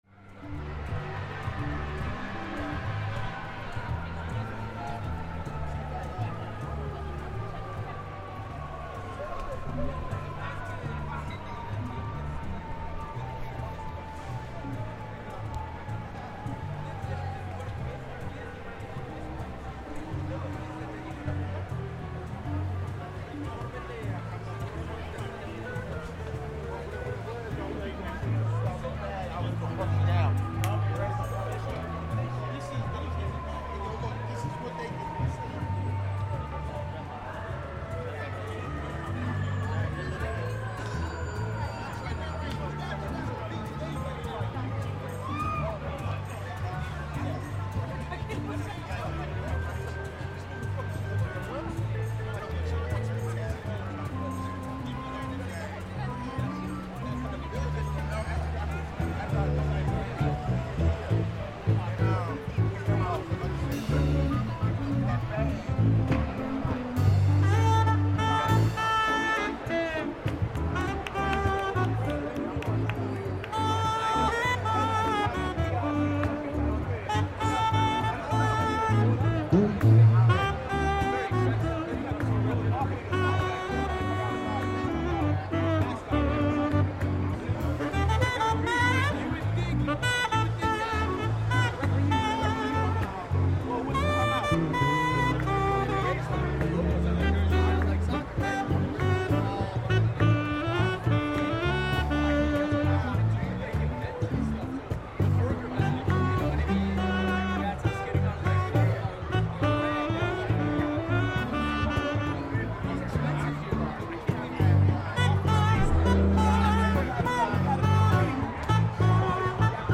Times Square is gaudy, garish and packed with thousands of tourists at all times - practically the only New Yorkers are nearby workers and street entertainers. But there's nearly always something going on - when we passed, there was some light jazz being performed at a bandstand in the centre of this temple to commercialism.